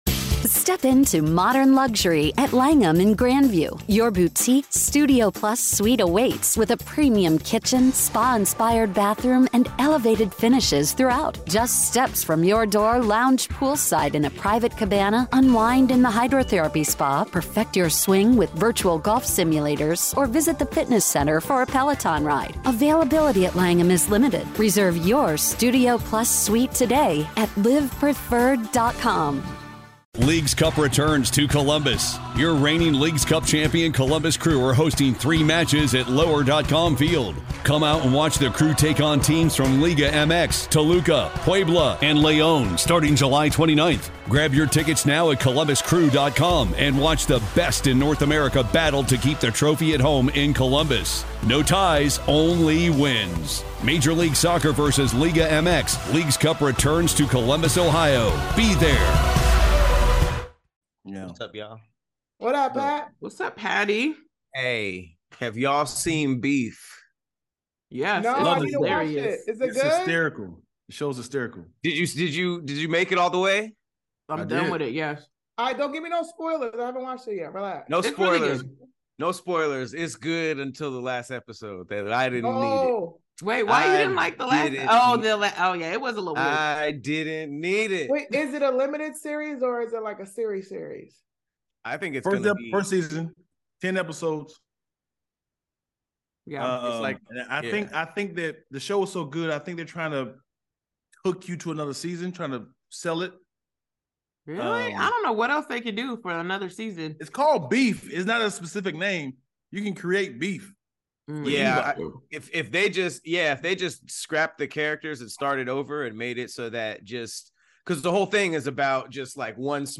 It’s a podcast with the OG SquADD! Each week, the SquADD will debate topics and vote at the end to see what wins.